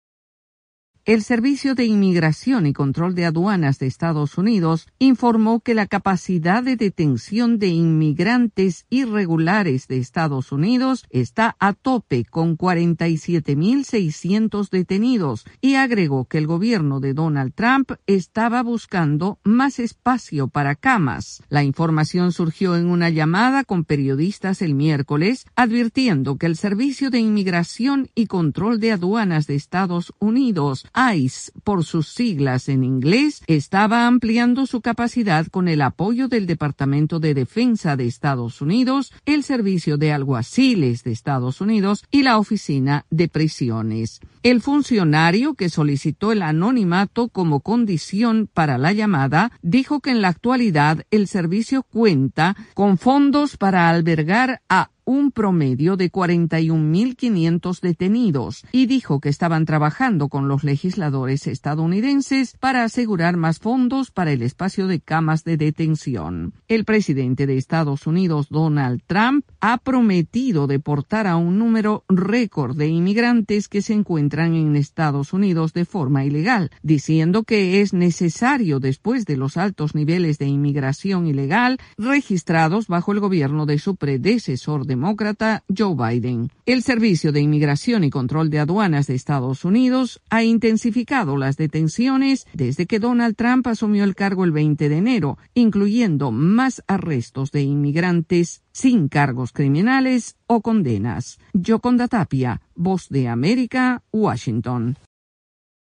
El número récord de detenciones de inmigrantes irregulares en EEUU ha saturado la capacidad de los centros de detención y autoridades buscan otros espacios. Desde la Voz de América en Washington informa